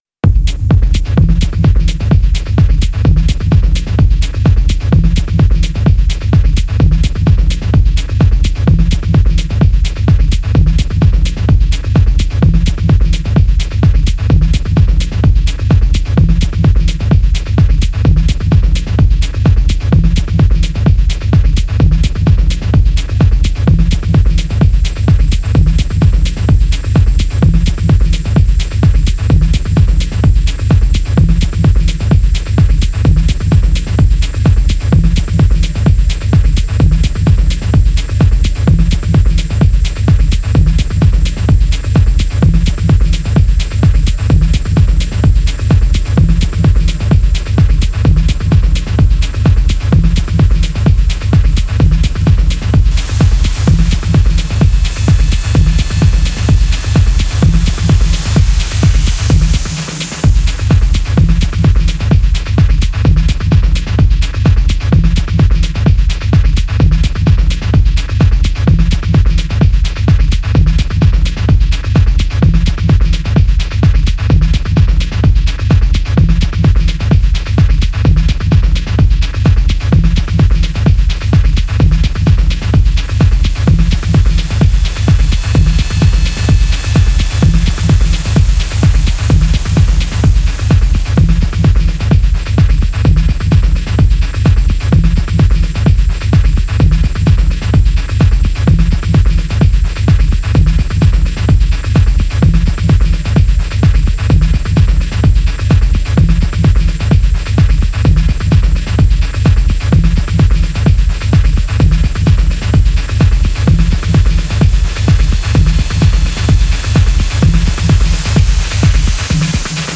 Genre: Tech House, Techno, Minimal.